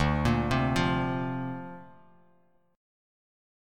Dm7#5 Chord
Listen to Dm7#5 strummed